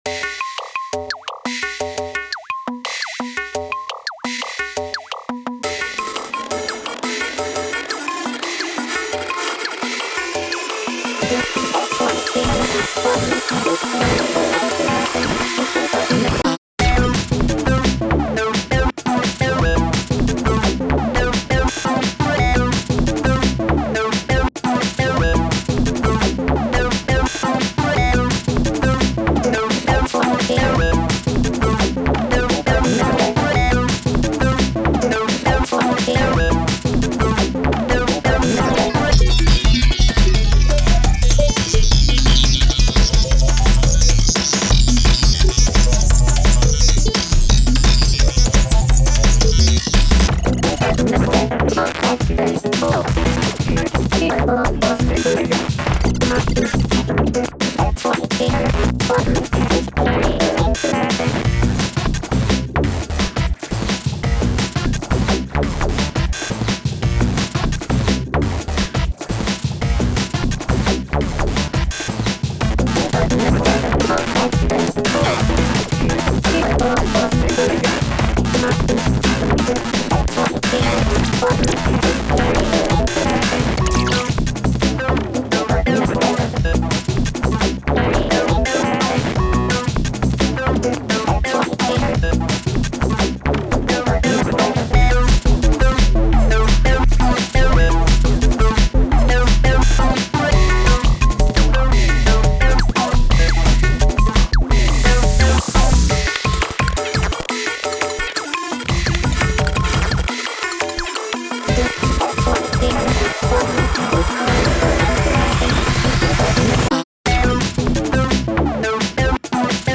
タグ: DnB EDM かっこいい 変わり種 疾走感 コメント: 壊れた機械人形の暴走をイメージして作ったDnB楽曲。